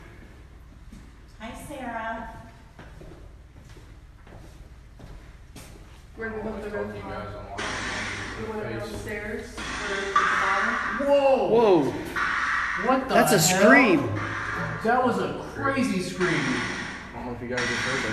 Scream coming from the staircase sound effects free download